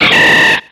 Cri de Chapignon dans Pokémon X et Y.